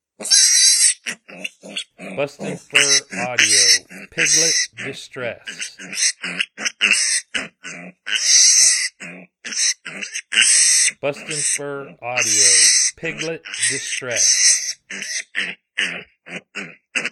BFA Piglet Distress
Baby wild piglet in distress. Used for calling hogs or predators.
BFA Piglet Distress Sample.mp3